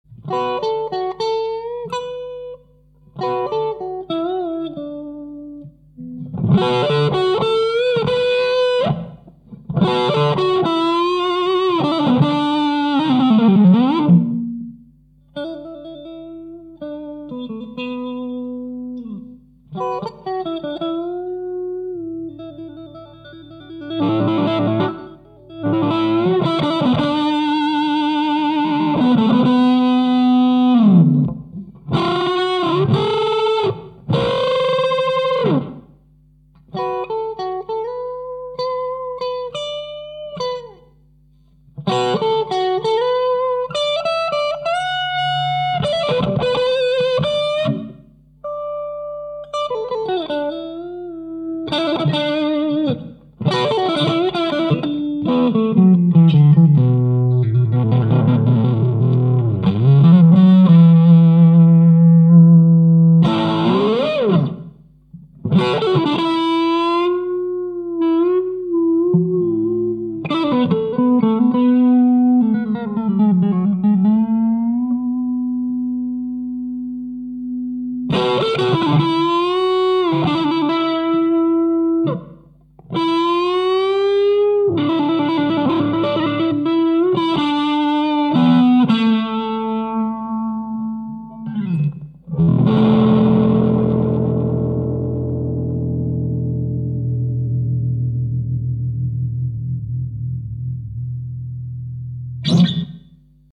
一応、JH FUZZ入れっぱなしでギター側のヴォリュームを
上げ下げしたサンプルもアップしておきます。
JH FUZZ Sample(1.6MbMP3)